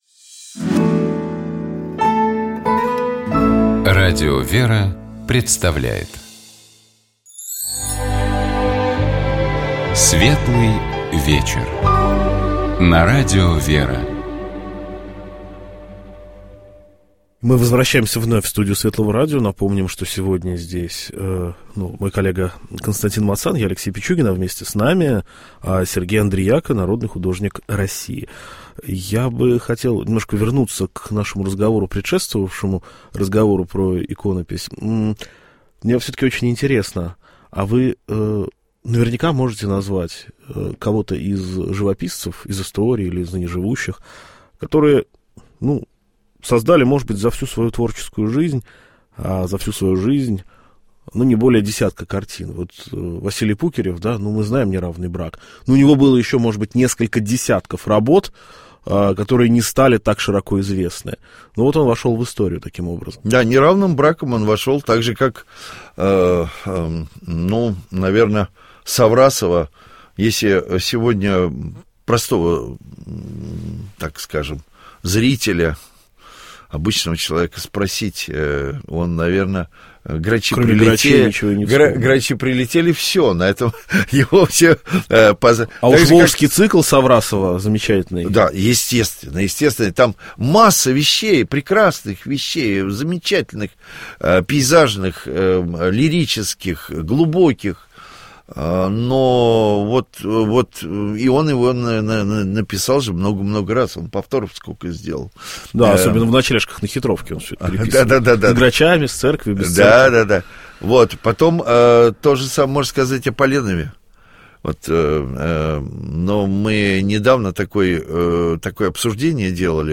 У нас в гостях был Народный художник России Сергей Андрияка.